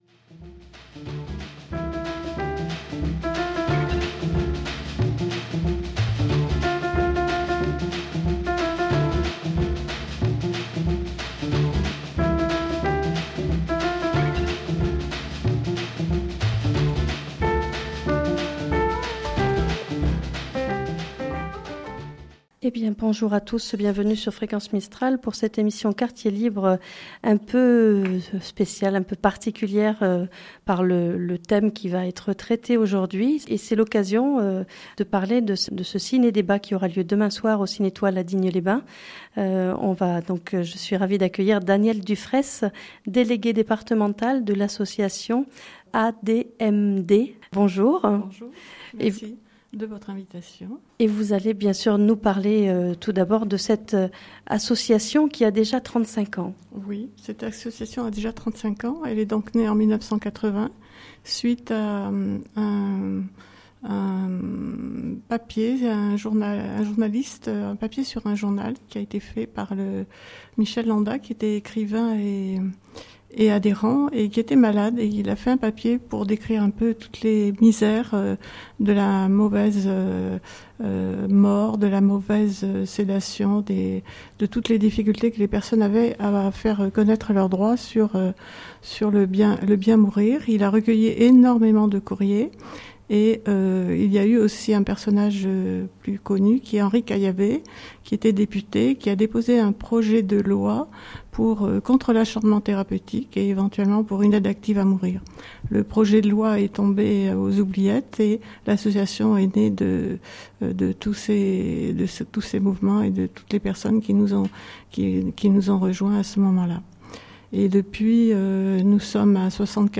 est l'invitée de cette émission "Quartier Libre"